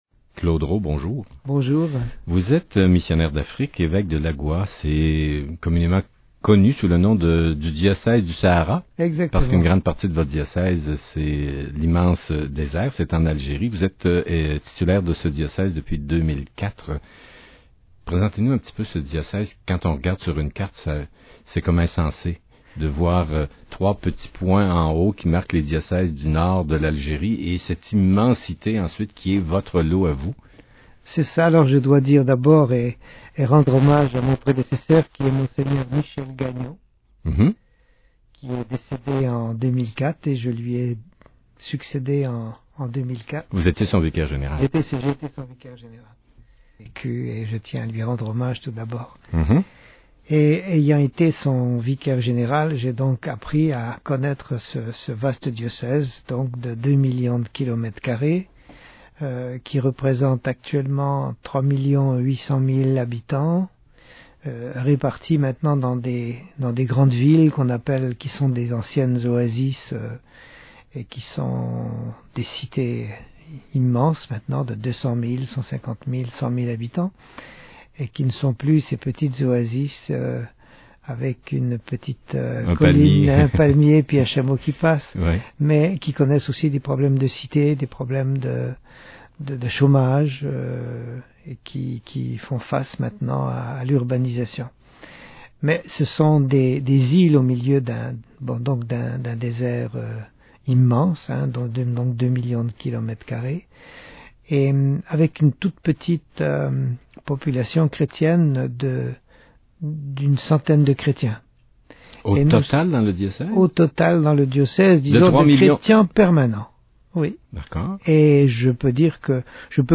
Au Canada, pays séculaire de migrations successives , il m’a été donné de faire quelques conférences avec débats, et quelques entretiens radiophoniques, soit à Montréal soit à Québec. Dans le contexte social, comme dans le contexte religieux, le flux de nouvelles migrations non occidentales ne fait qu’accentuer une certaine « crise d’identité », aussi bien chez les Québécois eux-mêmes, que chez les personnes venues de ces différents horizons.
crintervradiogalile.mp3